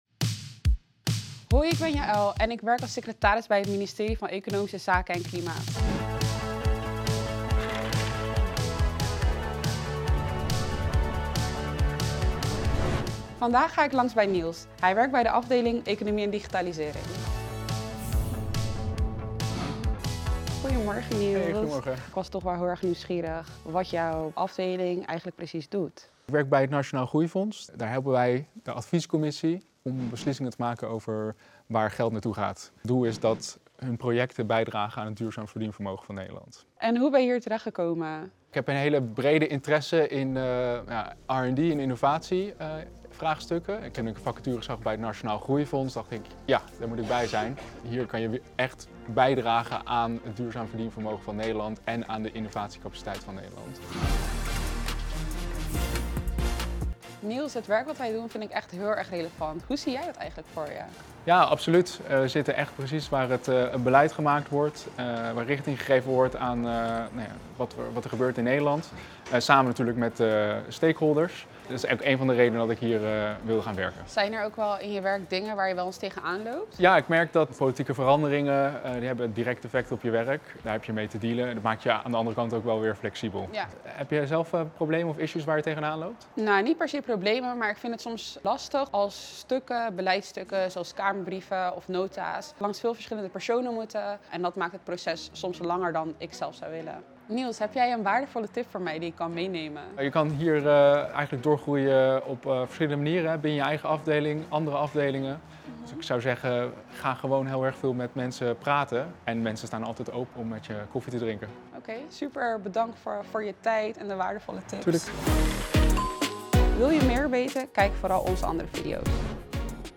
In de videoserie Op Je Plek Bij gaan starters in gesprek met ervaren collega’s over het werken en de loopbaanmogelijkheden bij het ministerie van Economische Zaken (EZ, voorheen het ministerie van Economische Zaken en Klimaat).